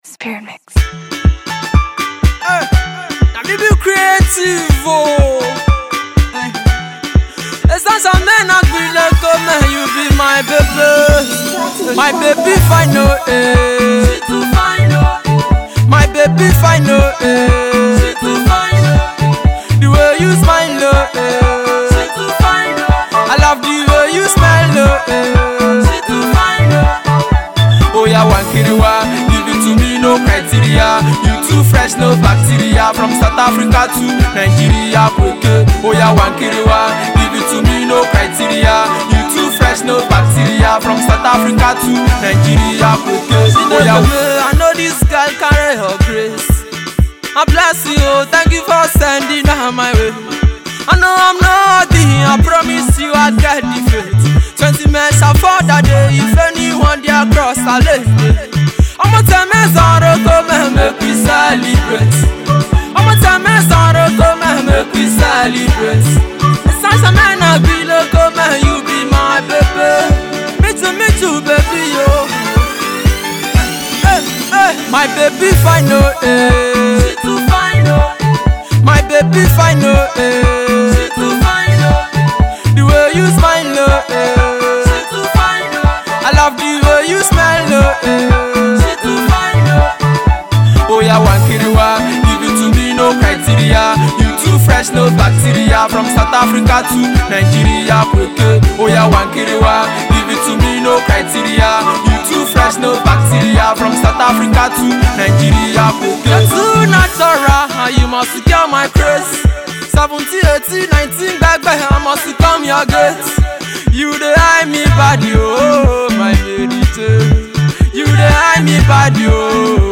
Afrobeats/Afro-Pop